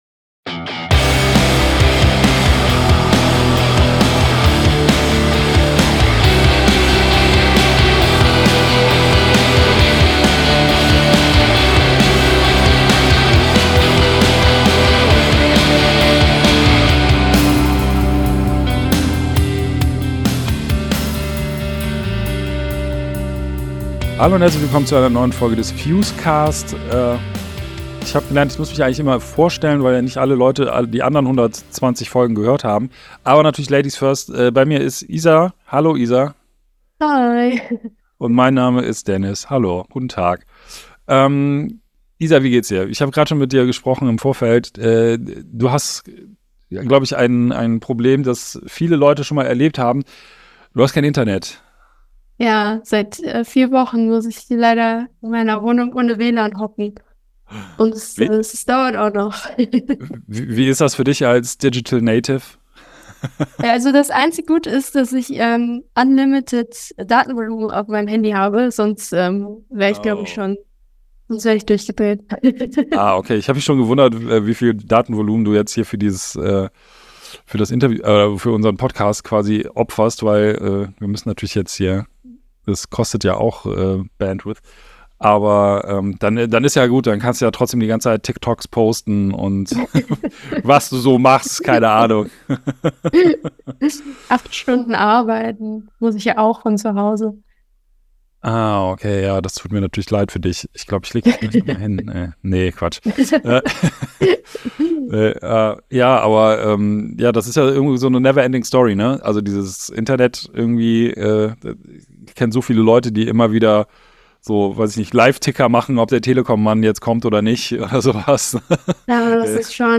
Außerdem im Interview: DOFLAME, das kanadische Ein-Mann-Hardcore Projekt aus Toronto.